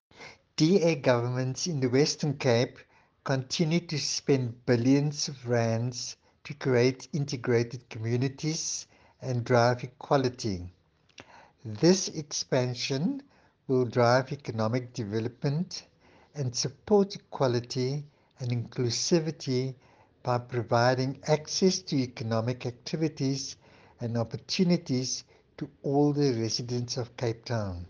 audio clip from MPP Derrick America